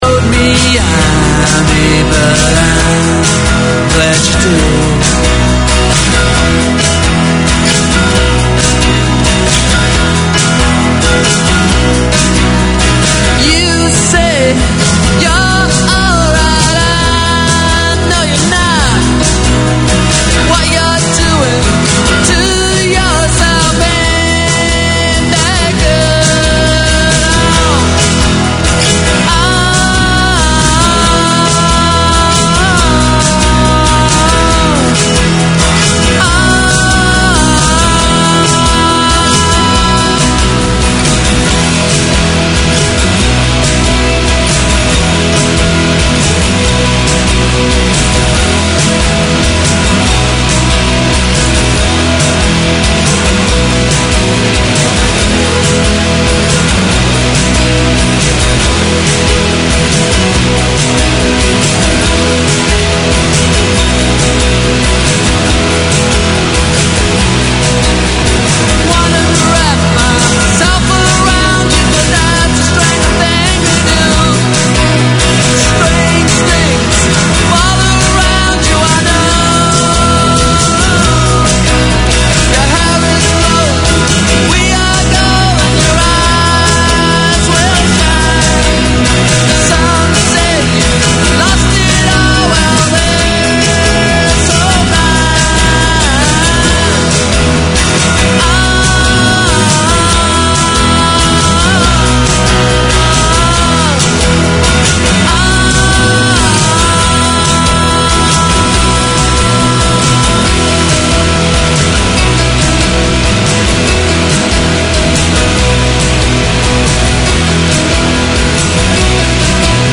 Unfiltered Conversations: Chai & Chat, is a safe and open space where young South Asian girls, can openly discuss, debate, and ask questions about relationships, culture, identity, and everything in between. They are on-air to break the stigma, share experiences, and support each other through honest conversations over a cup of chai.